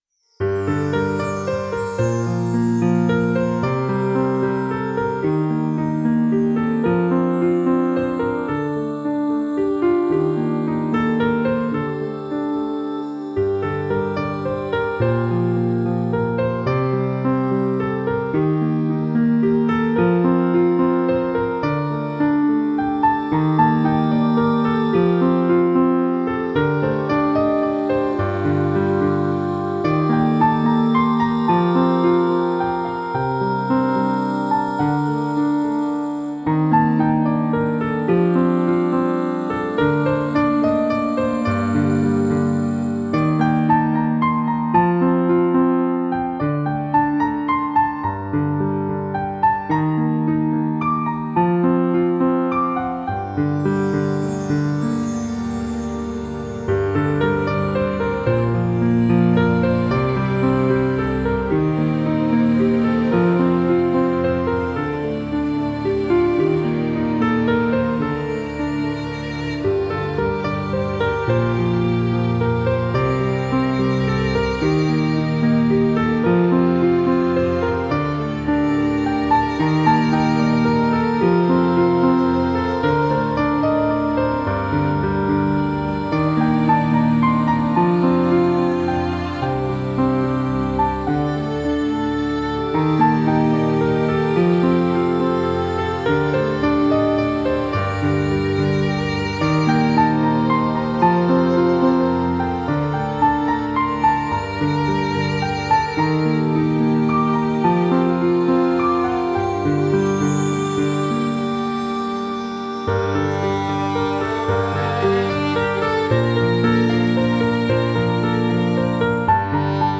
Genere: New Age.